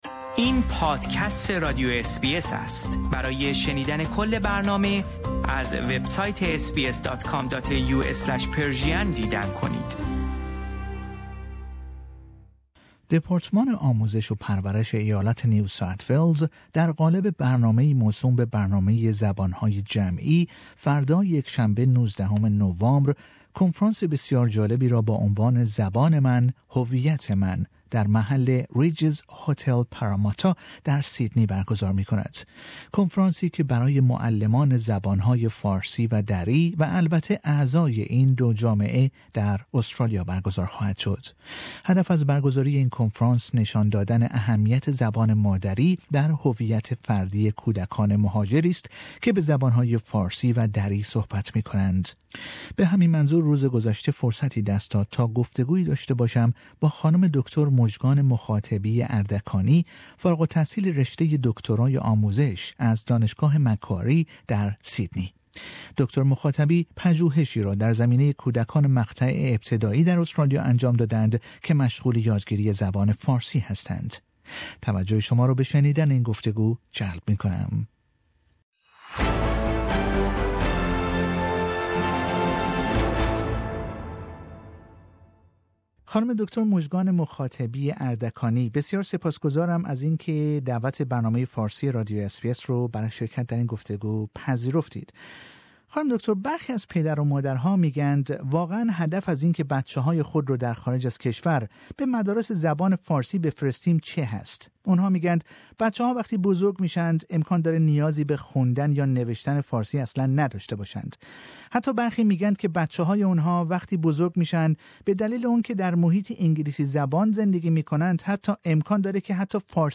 اس بی اس فارسی